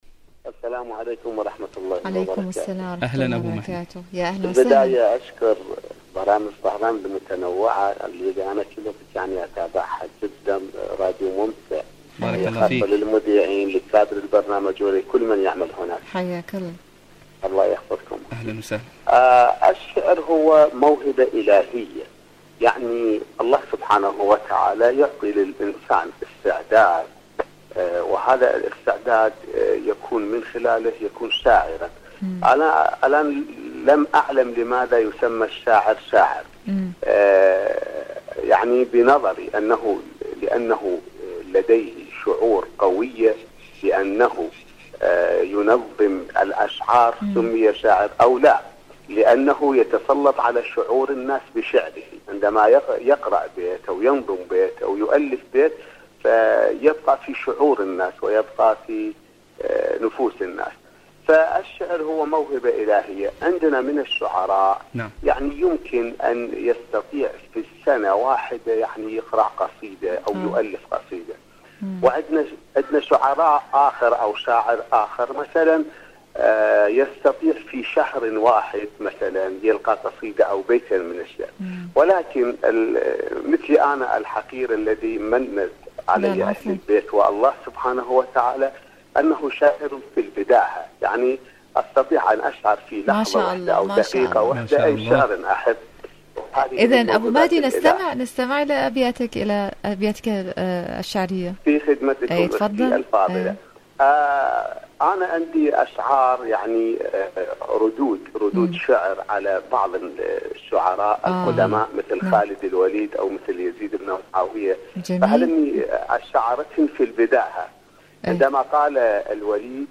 برنامج: دنيا الشباب / مشاركة هاتفية